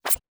fnl/Assets/Extensions/Advanced_UI/User_Interface/Tab_Select/Tab Select 16.wav
Tab Select 16.wav